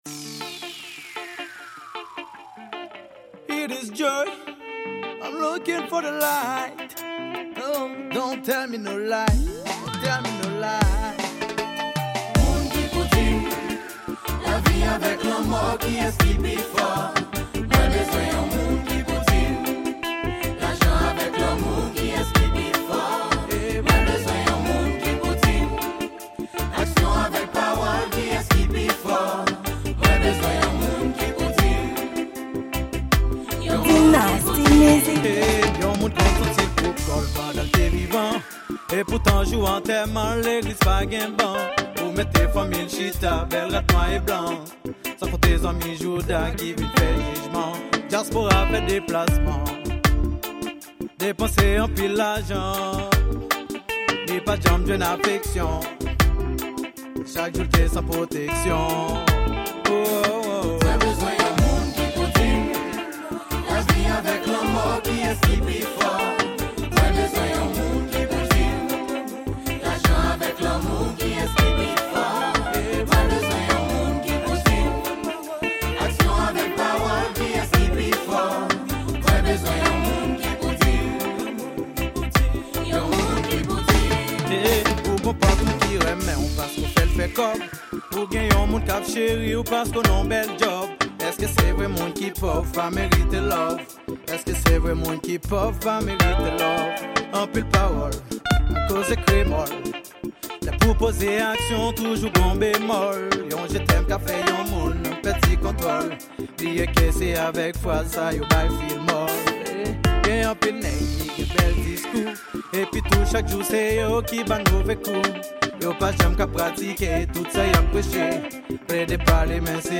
Genre: Reggae